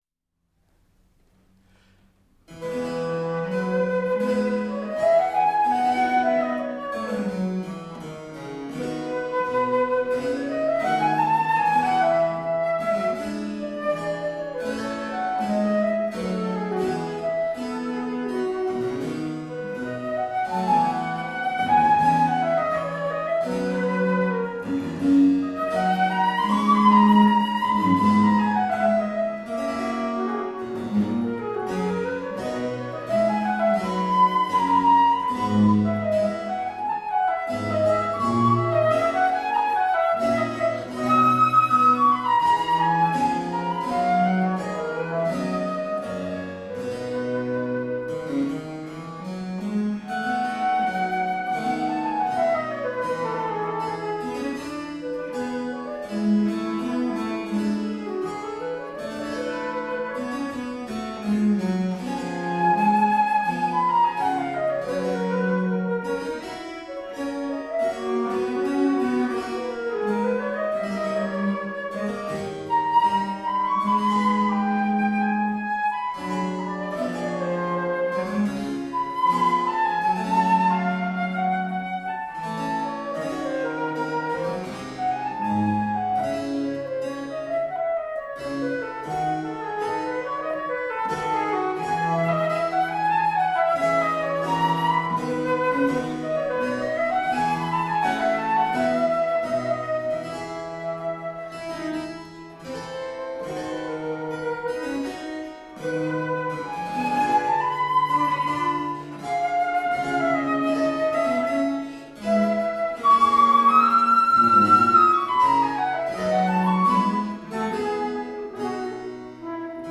Flautists with finesse, intelligence and grooves.
recorded at the Hakodate City Community Centre 2011.
Classical, Chamber Music, Baroque, Instrumental